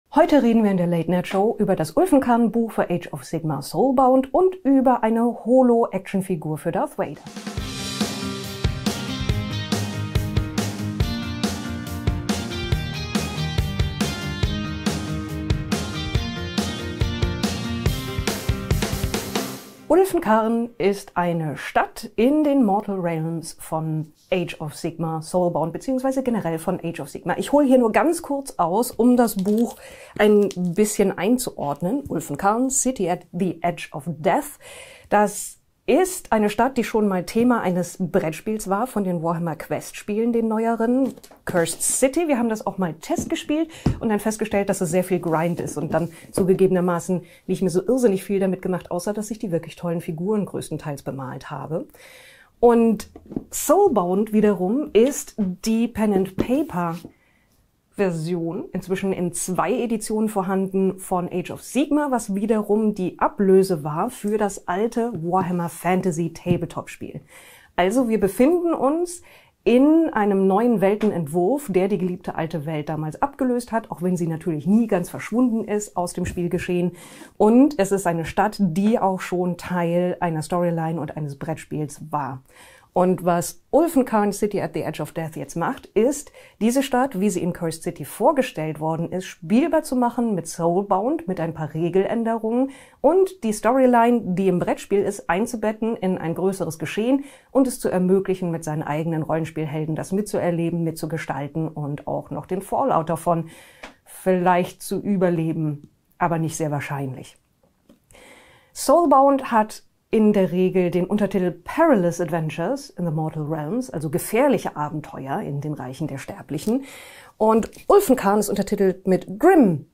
1 Cthulhu: Das Bildnis der Ewigkeit: Pen and Paper live aus dem Museum Ägyptischer Kunst